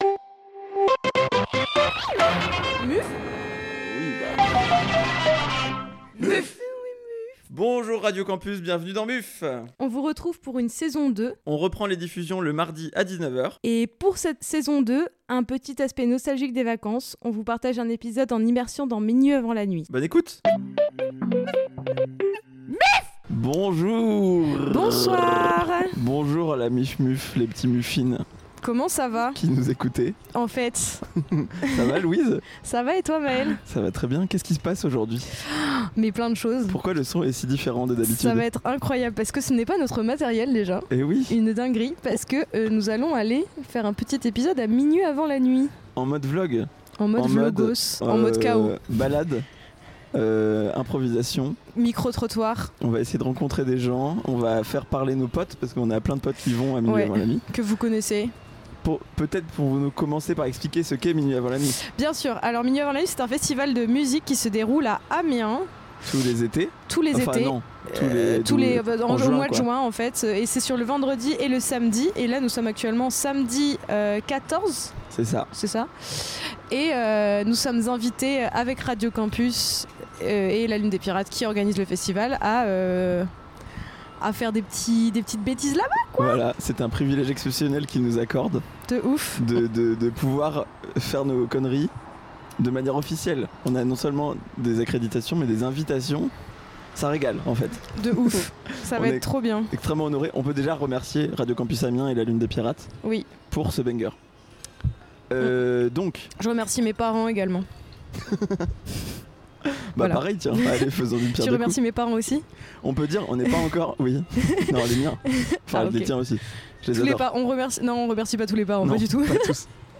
On revient sur Radio Campus Amiens avec un épisode enregistré il y a quelques mois à Minuit avant la Nuit, en immersion totale dans le festival…